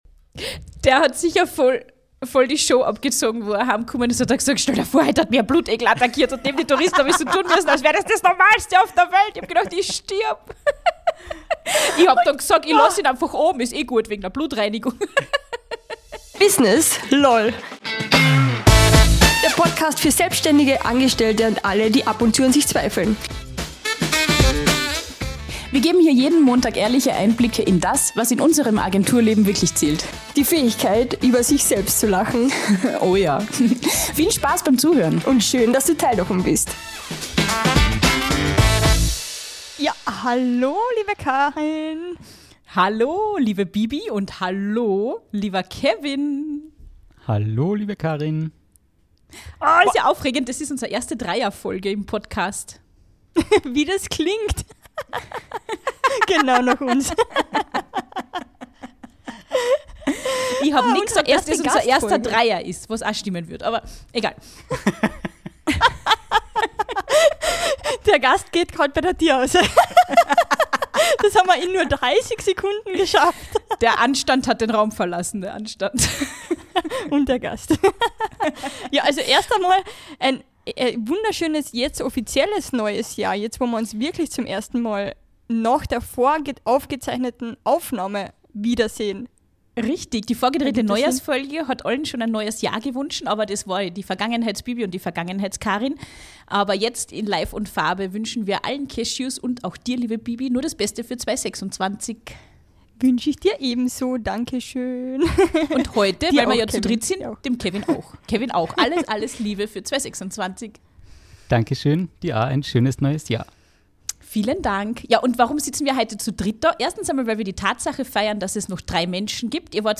Viel Freude mit unserer ersten Dreier-Folge.